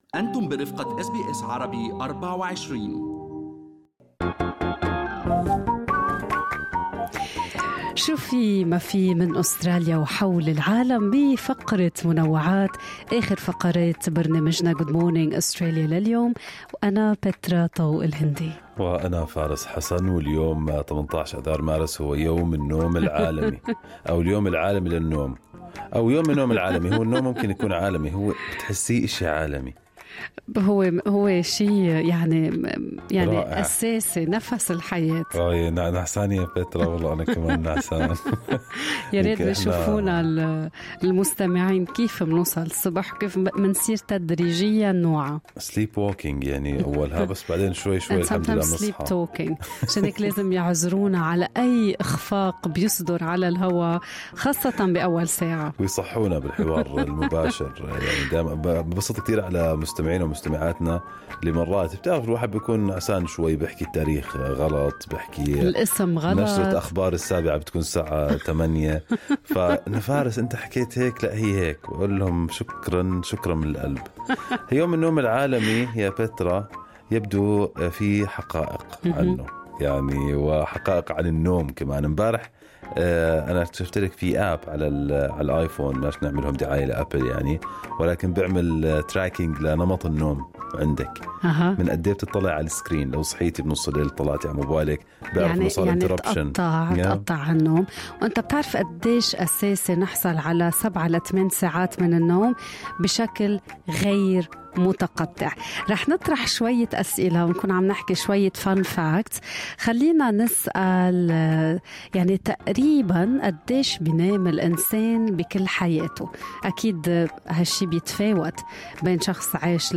نقدم لكم فقرة المنوعات من برنامج Good Morning Australia التي تحمل إليكم بعض الأخبار والمواضيع الخفيفة.